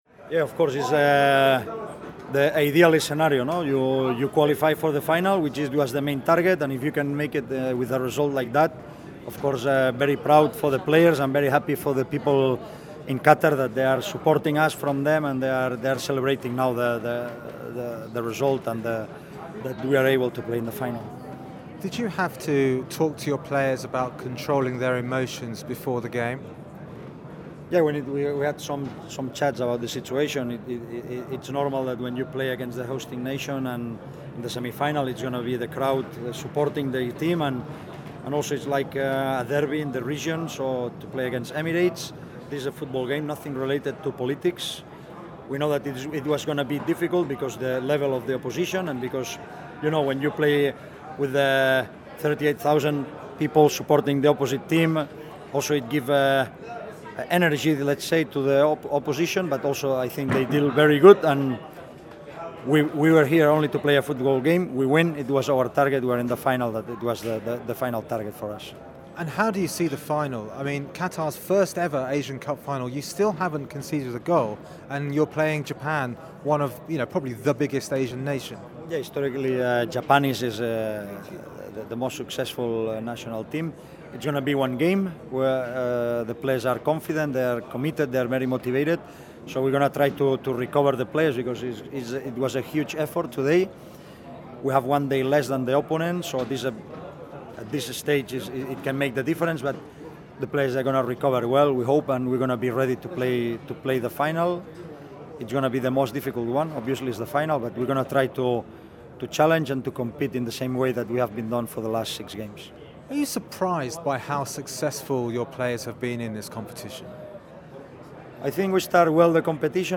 Qatar coach Felix Sanchez after Qatar 4-0 UAE